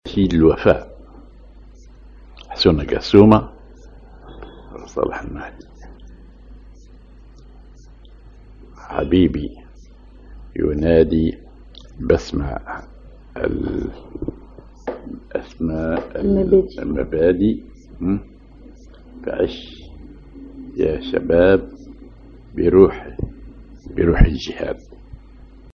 Maqam ar ماجور على الراست
genre نشيد